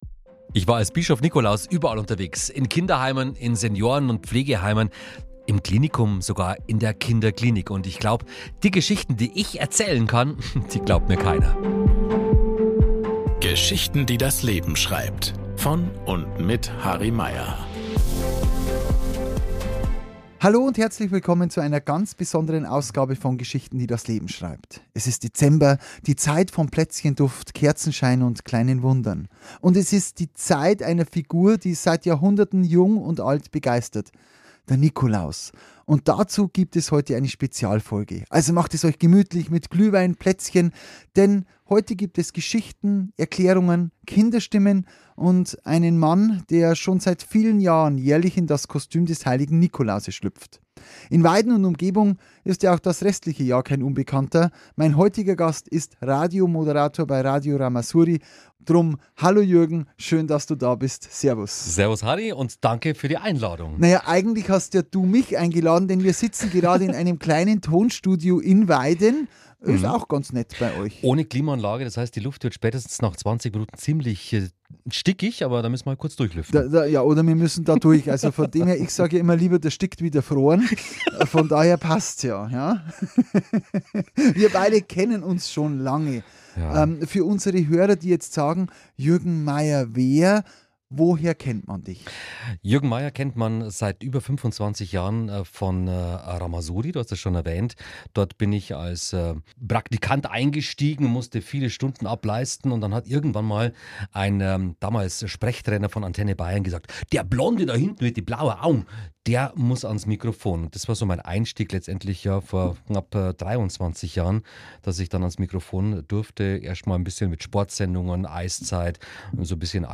Und weil Weihnachten ohne Kinderstimmen unvollständig wäre, hören wir kleine Kinder über ihre Wünsche, Fantasien und die große Frage sprechen: Wo wohnt der Nikolaus eigentlich?
Eine warmherzige, weihnachtlich angehauchte Spezialfolge für alle, die sich den Zauber des Nikolauses bewahrt haben.